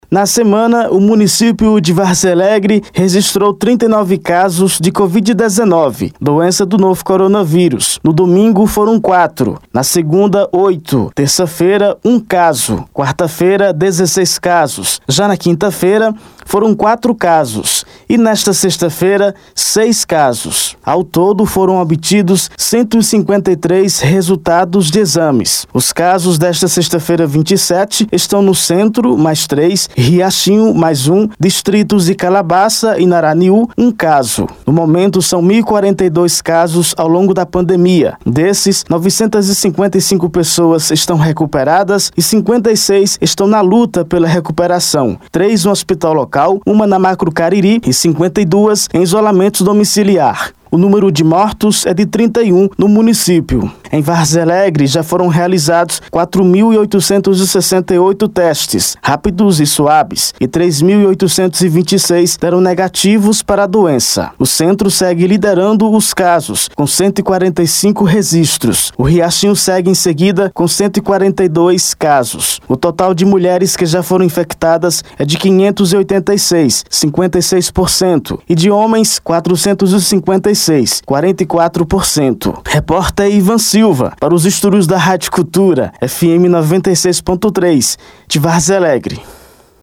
Na semana o município de Várzea Alegre registrou 39 casos de Covid-19, doença do novo coronavírus. Confira a reportagem completa no áudio: